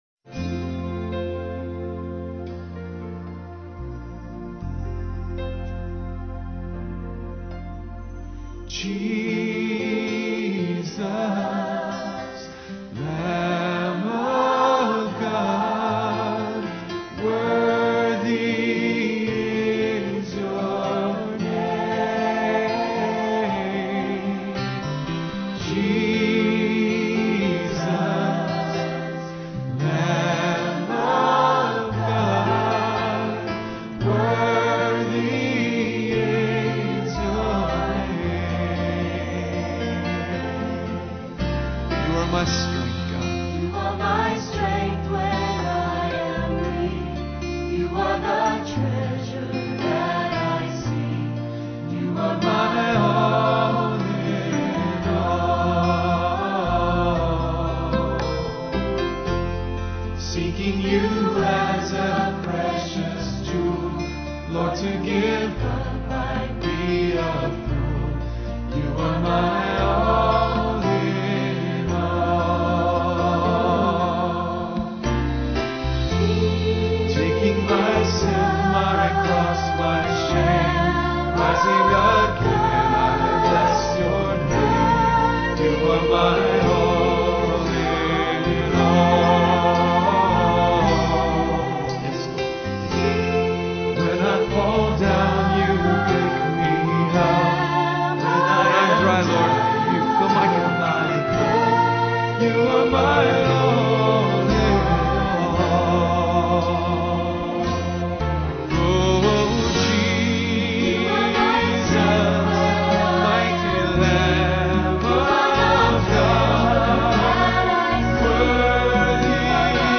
Song of Praise : You Are My All In All